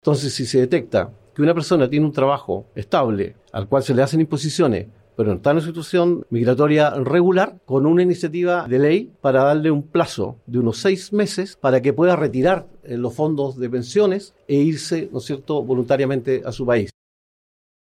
En conversación con Bío Bío TV, Alvarado defendió ambas designaciones y descartó cualquier irregularidad o conflicto de interés, subrayando que se trata de procesos habituales en la conformación de un gabinete presidencial.